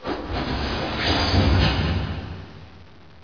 mission_sound_amb00tmachine06.wav